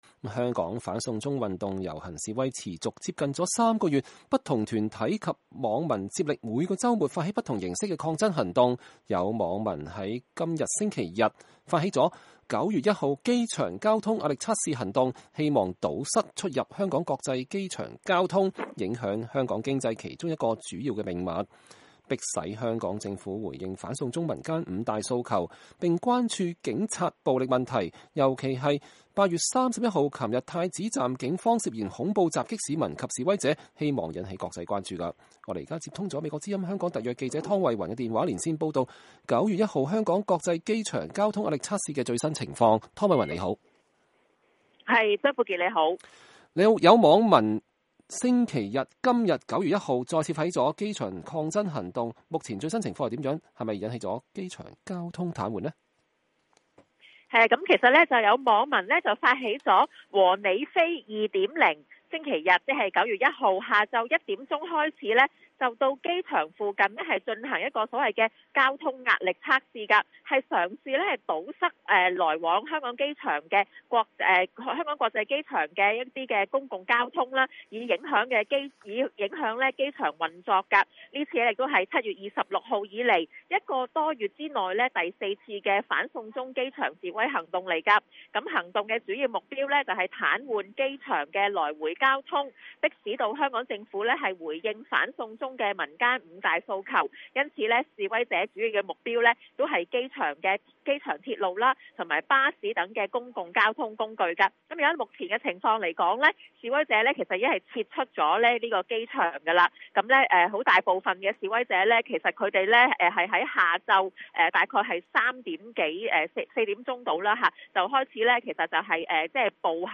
香港反送中運動第4次機場示威現場報導